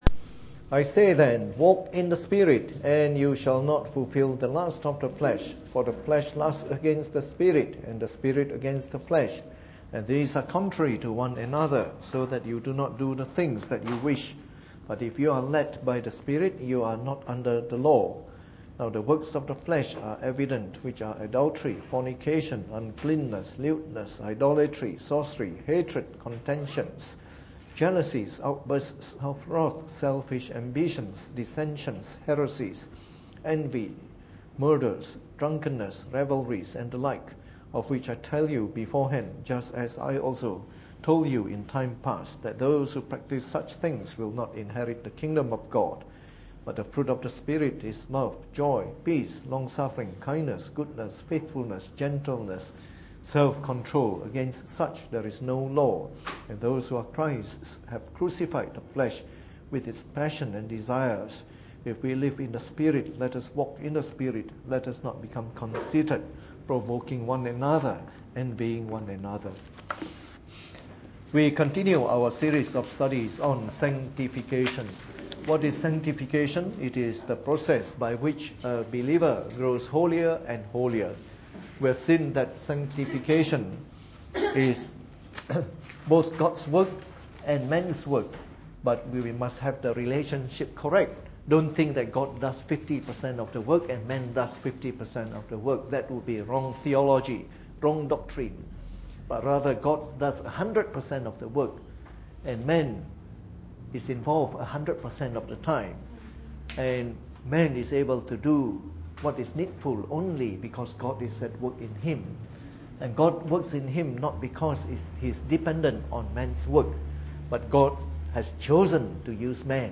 Preached on the 28th of January 2015 during the Bible Study, from our series of talks on Sanctification.